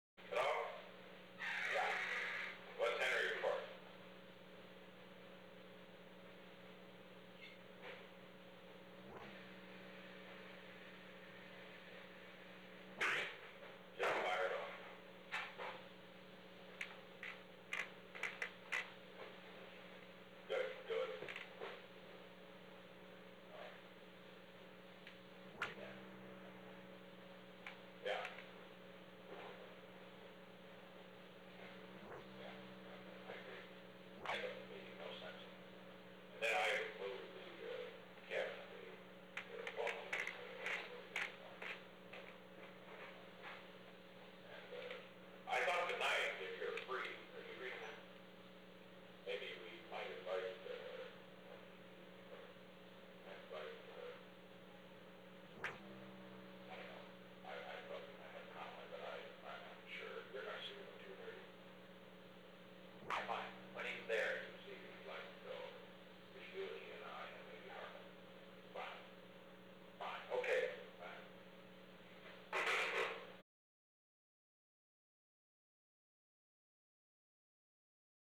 Secret White House Tapes
Conversation No. 443-7
Location: Executive Office Building
Alexander M. Haig, Jr. talked with the President.